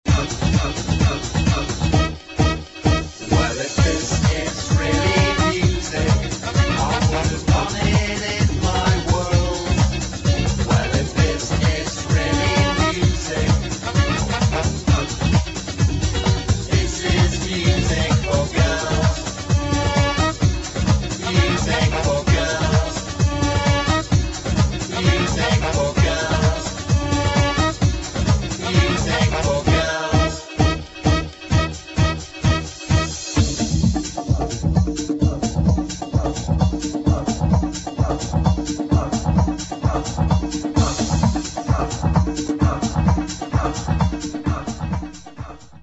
[ HOUSE / ELECTRO ]